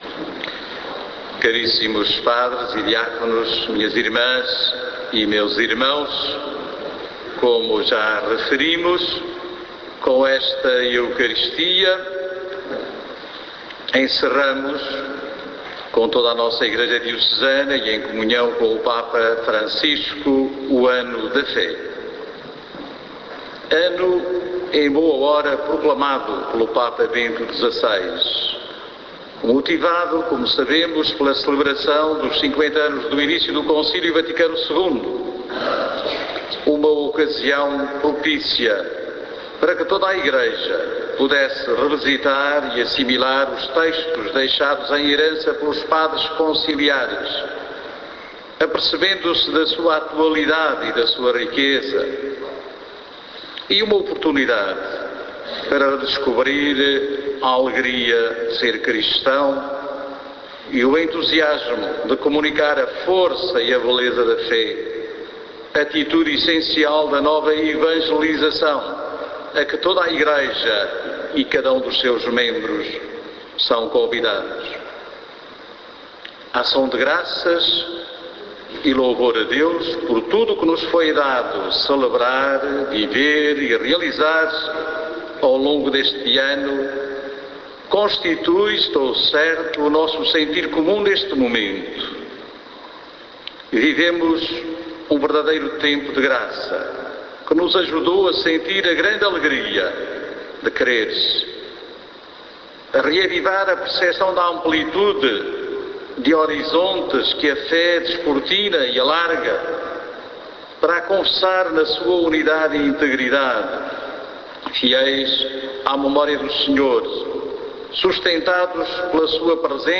Homilia_encerramento_ano_fe.mp3